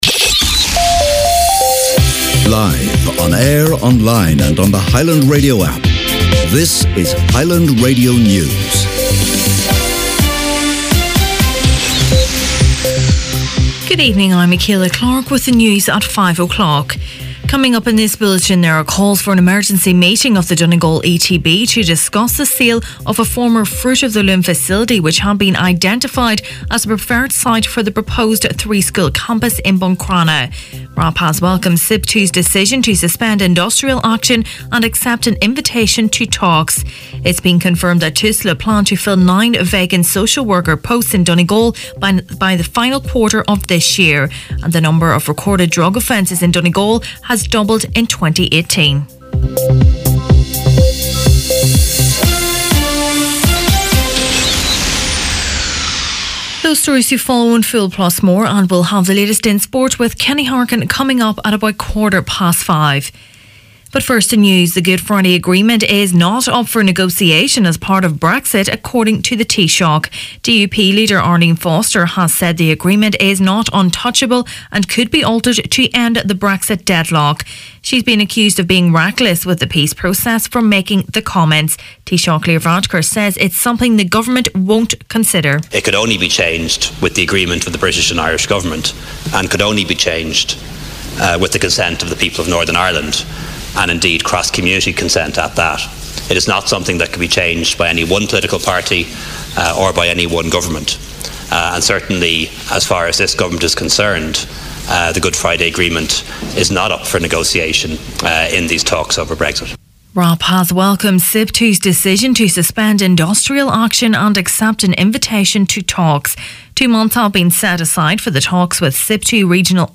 Main Evening News, Sport and Obituaries Tuesday October 2nd